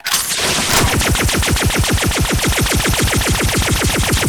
laser.ogg